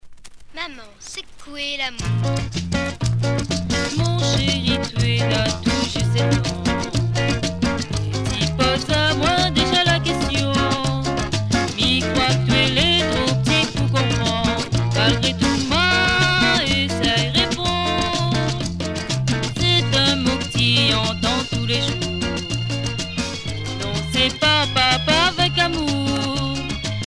danse : séga
Pièce musicale éditée